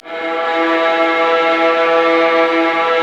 Index of /90_sSampleCDs/Roland LCDP13 String Sections/STR_Violas FX/STR_Vas Sul Pont